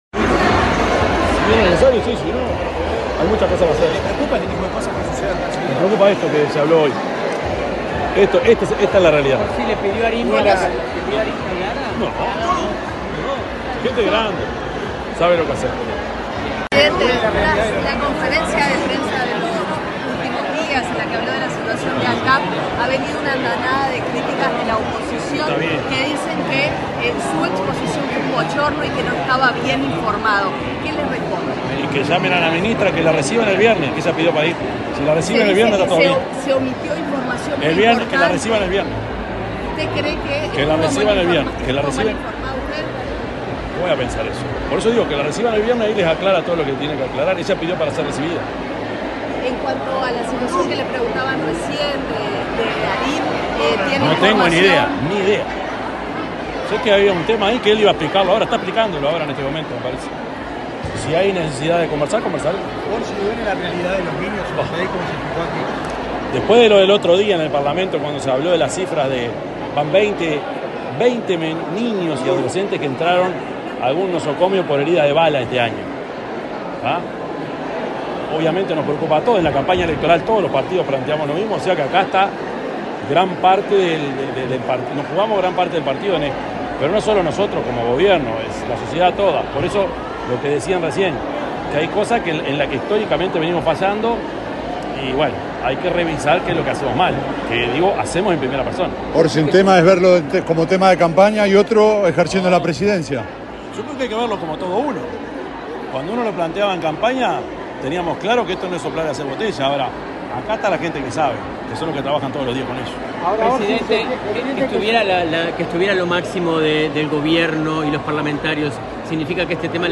Declaraciones del presidente de la República, Yamandú Orsi
El presidente de la República, profesor Yamandú Orsi, dialogó con la prensa luego de participar del acto de asunción de las autoridades del Instituto